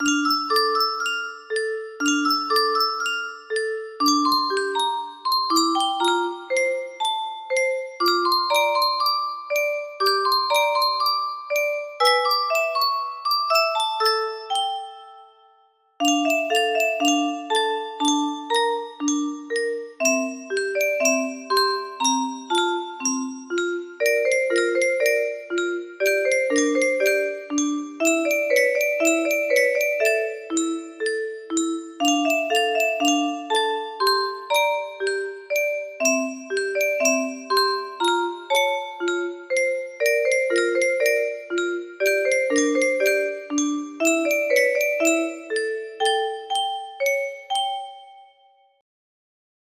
Hendro music box melody
Grand Illusions 30 (F scale)